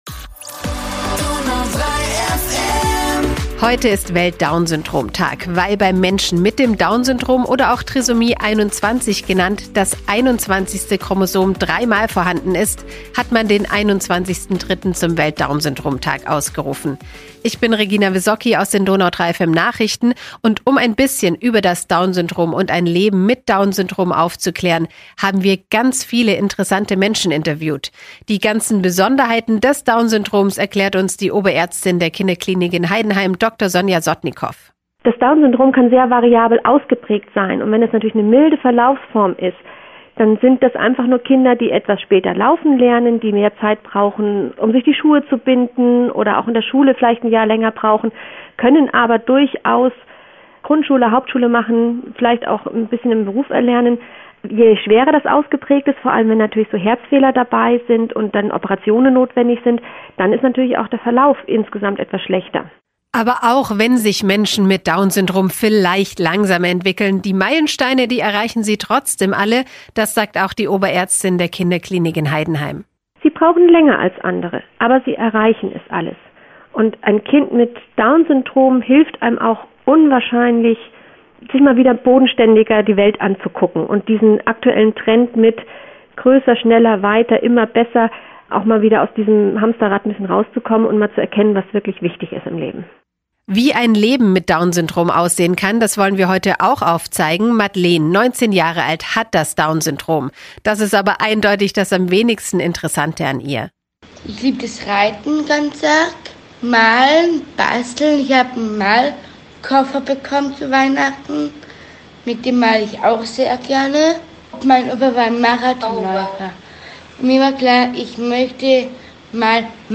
Interviews zum Welt-Down-Syndrom-Tag 2024